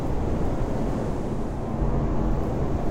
Erekir turret SFX (of varying quality)
glow.ogg